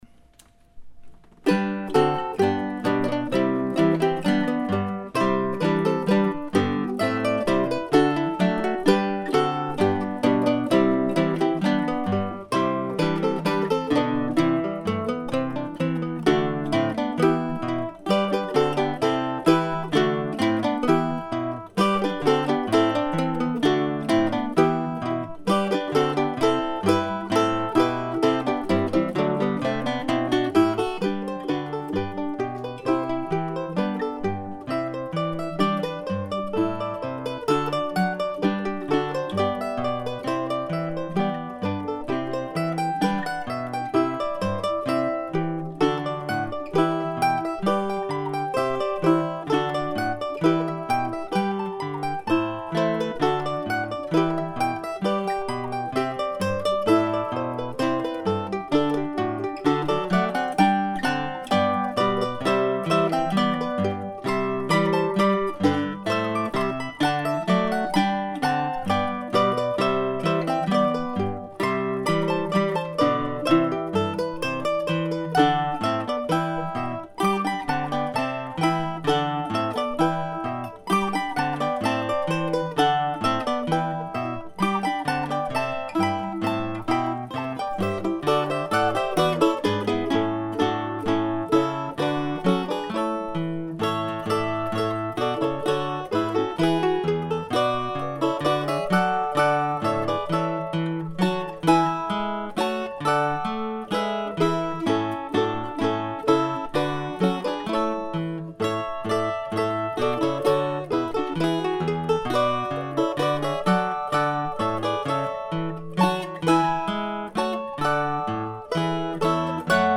That report led me to dust off this piece for mandolin quartet (or mandolin orchestra) that I put together in 2002 by combining two short wedding marches that I had written long ago.
The recording leaves much room for improvement but I settled for getting most of the notes correct.
I played guitar for the bass line and octave mandolin for the mandola part.